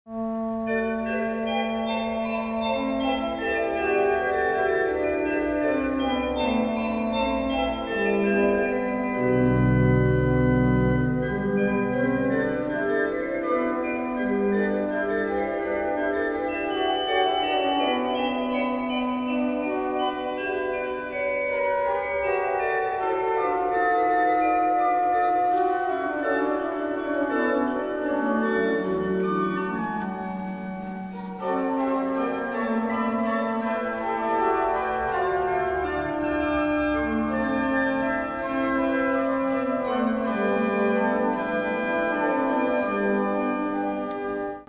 Concert sur l'orgue Aubertin de l'église Saint-Louis à Vichy
Les extraits montrent quelques échantillons des sonorités particulières de l'orgue.